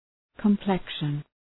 Προφορά
{kəm’plekʃən}